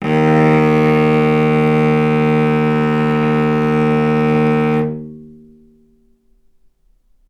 vc-D#2-ff.AIF